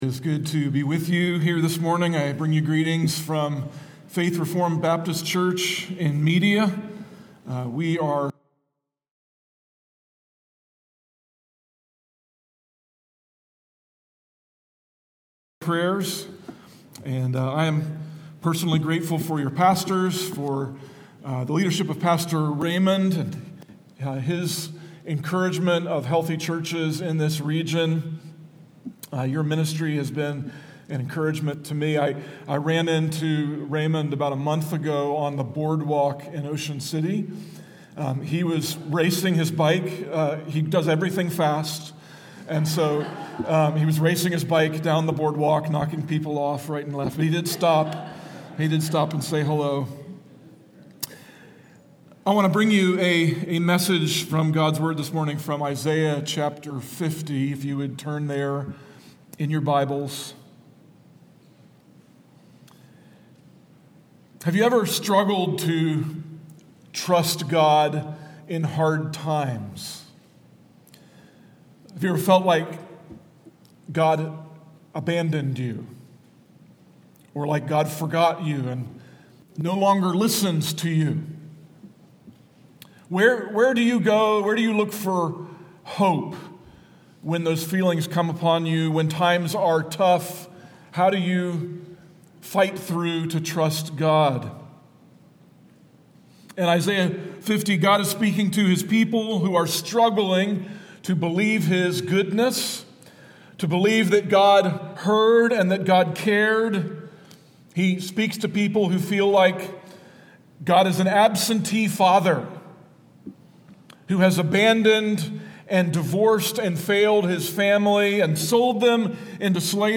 Sermon-727.mp3